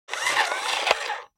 جلوه های صوتی
دانلود صدای ربات 41 از ساعد نیوز با لینک مستقیم و کیفیت بالا